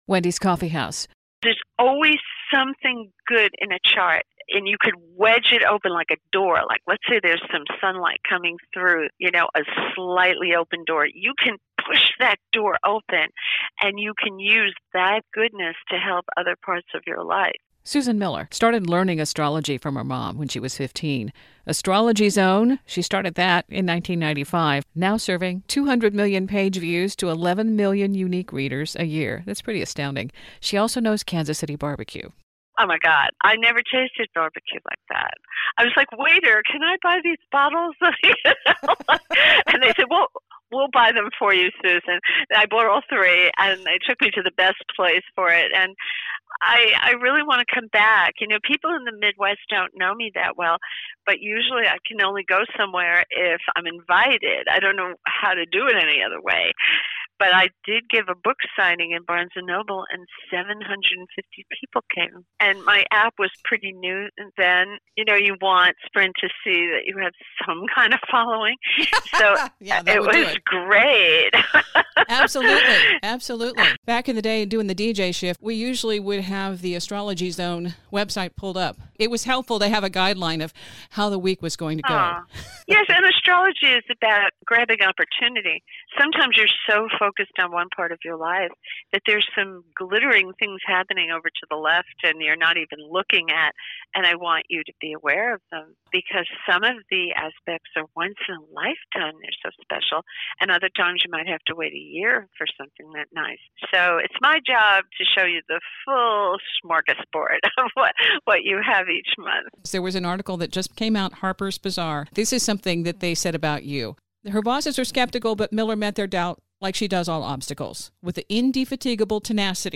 When I mentioned this interview was coming up, I found out I am not alone.